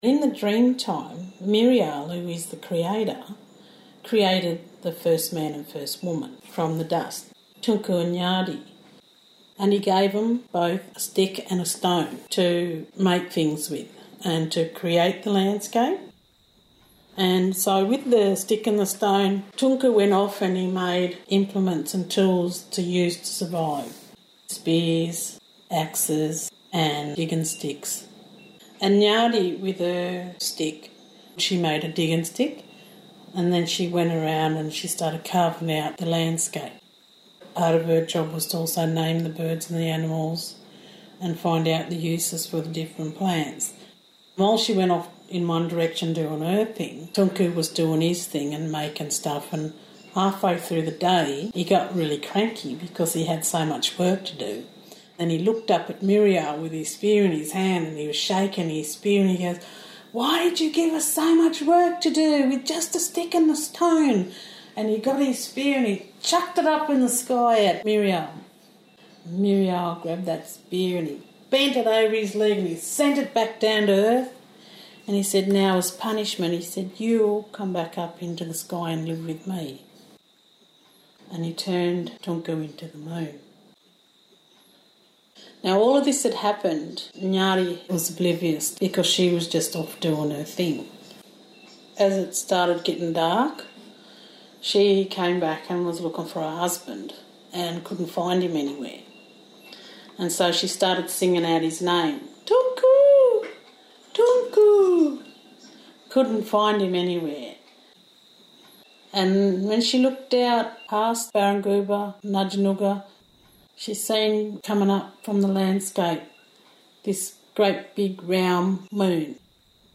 Toonku and Ngyardi creation story